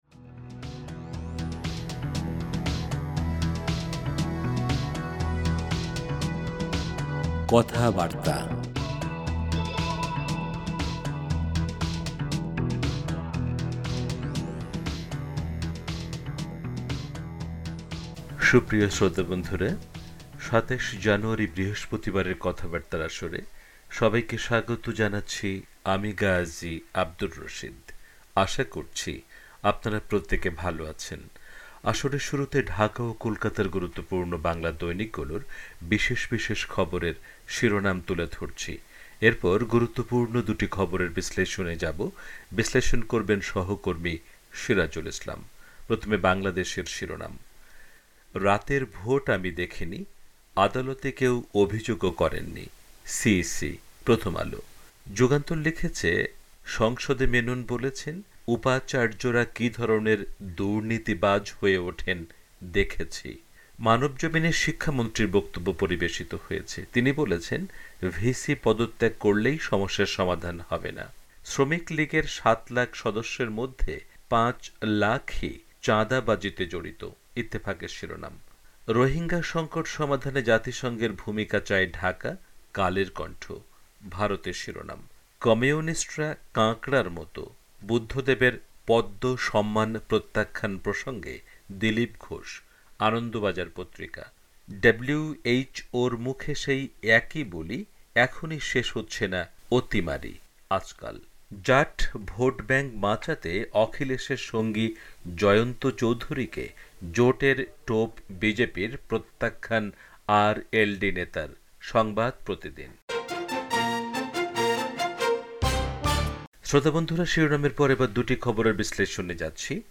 আসরের শুরুতে ঢাকা ও কোলকাতার গুরুত্বপূর্ণ বাংলা দৈনিকগুলোর বিশেষ বিশেষ খবরের শিরোনাম তুলে ধরছি। এরপর গুরুত্বপূর্ণ দুটি খবরের বিশ্লেষণে যাবো।